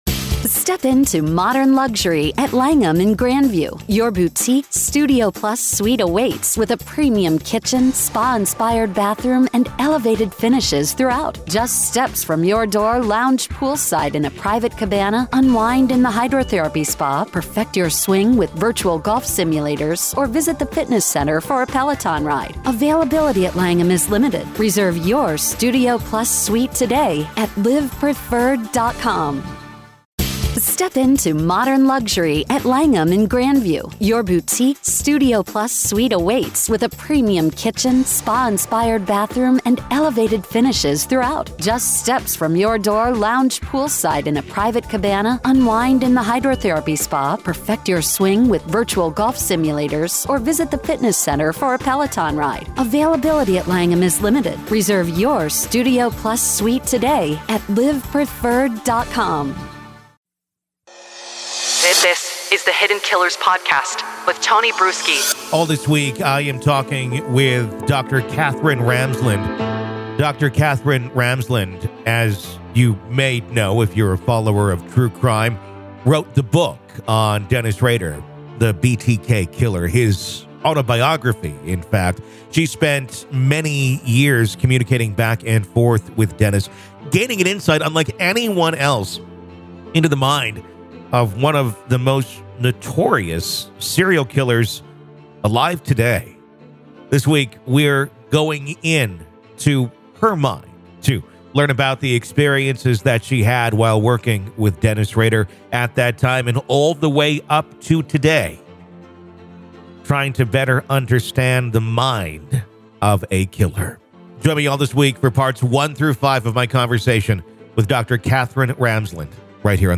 Inside The Mind Of BTK: Katherine Ramsland Interview Part 5